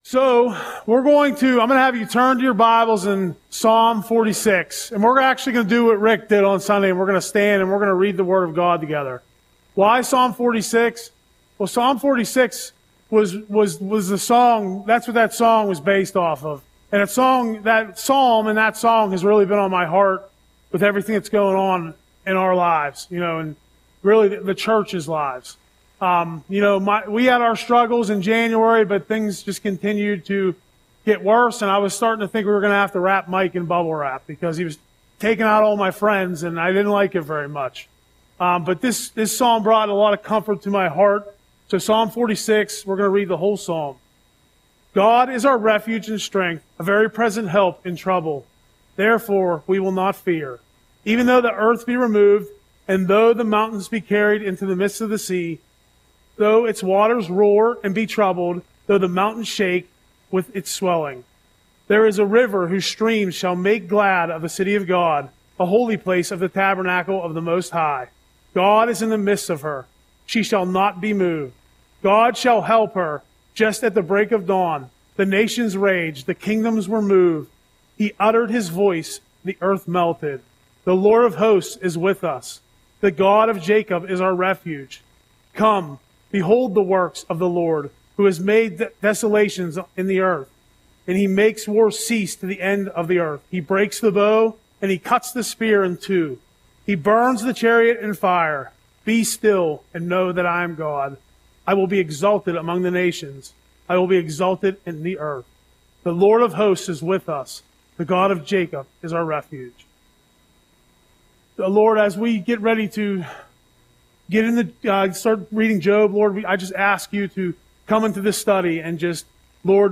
Audio Sermon - April 9, 2025